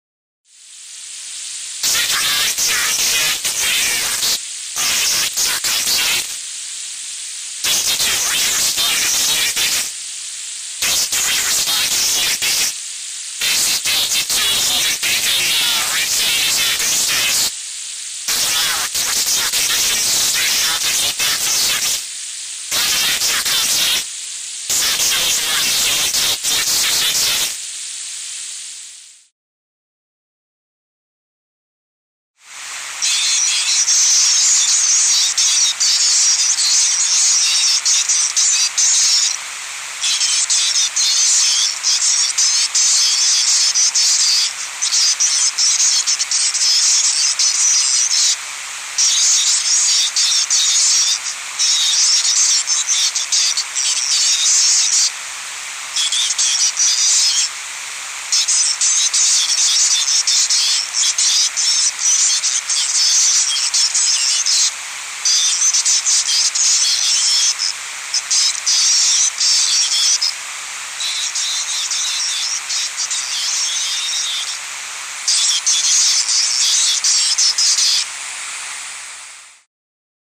Звуки радио, помех
Радиовещание сквозь помехи